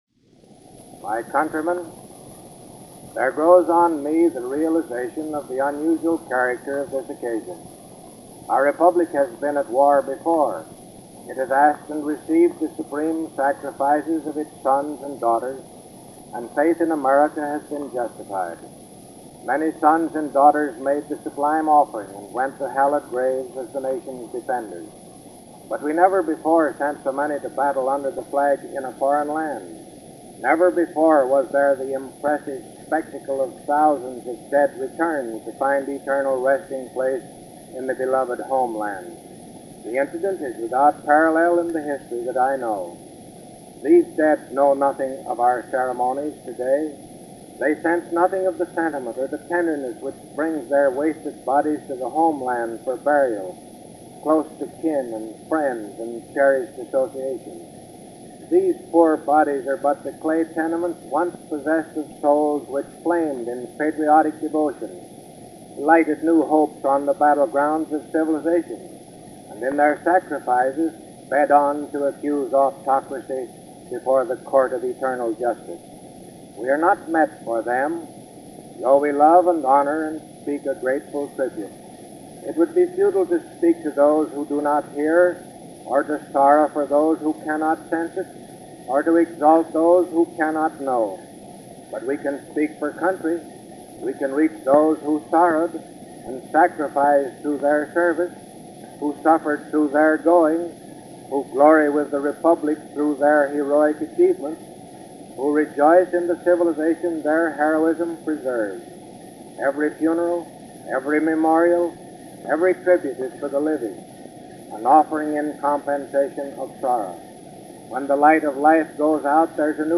Here is that address as given by President Harding from Hoboken New Jersey on the occasion of the arrival of 5, 212 war dead from Europe from May 23, 1923.